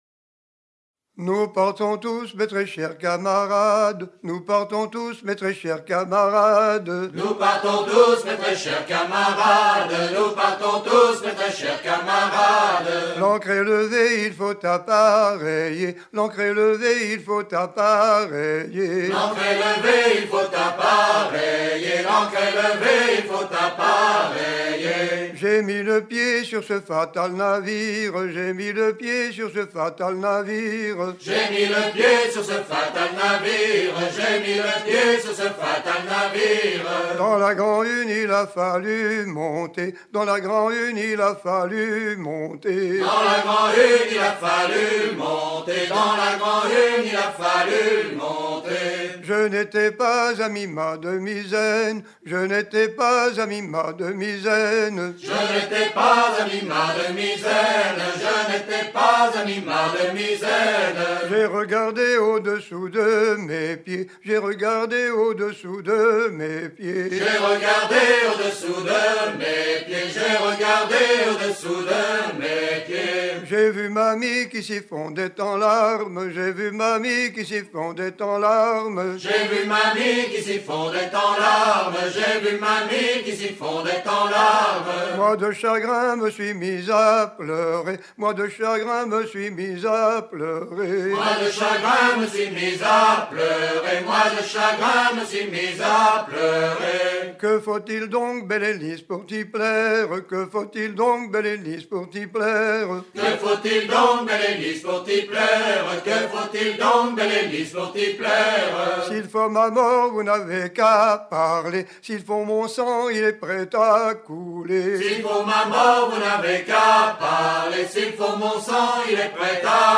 Mémoires et Patrimoines vivants - RaddO est une base de données d'archives iconographiques et sonores.
danse : rond
Genre strophique
Pièce musicale éd